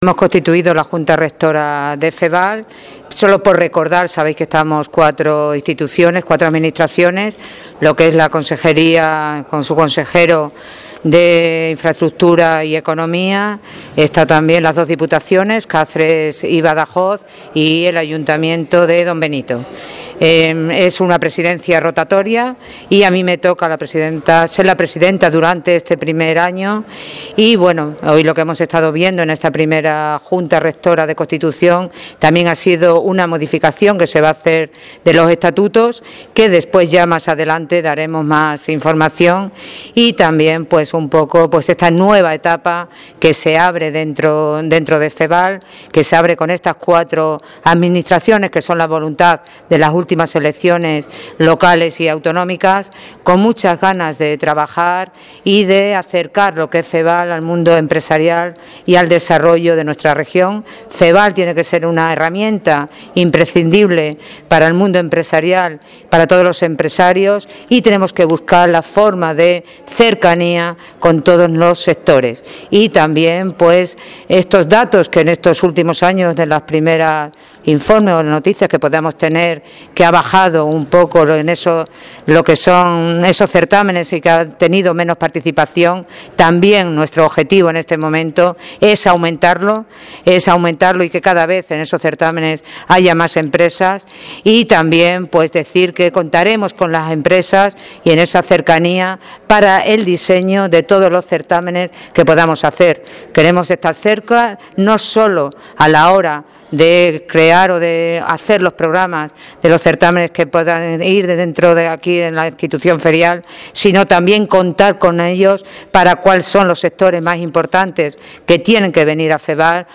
CORTES DE VOZ
Charo_Cordero-FEVAL.mp3